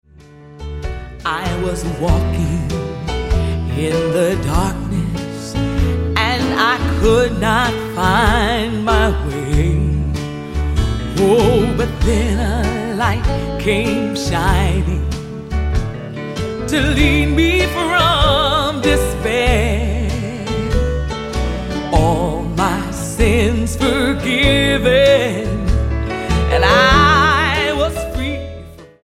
STYLE: Gospel
with a band re-creating a timeless, bluesy accompaniment